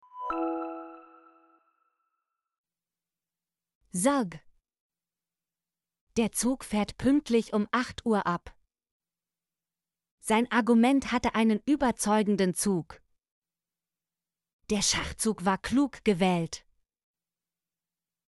zug - Example Sentences & Pronunciation, German Frequency List